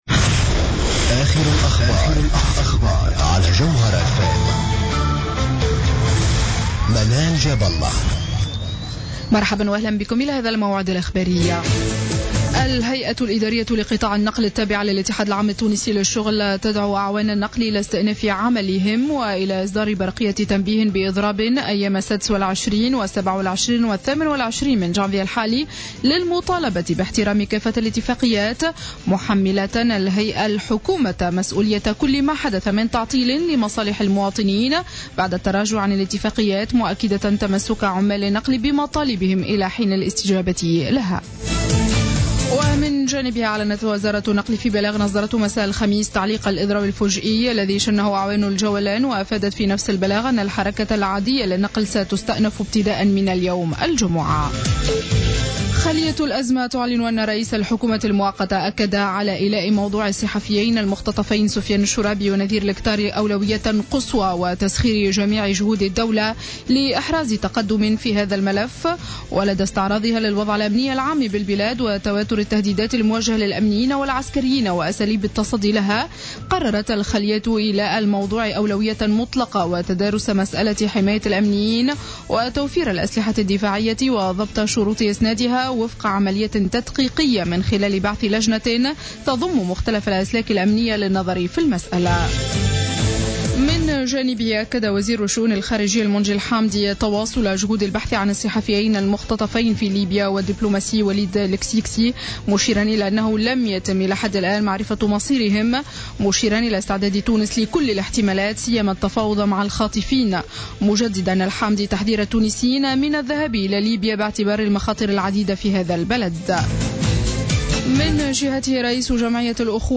نشرة أخبار منتصف الليل ليوم الجمعة 16-01-15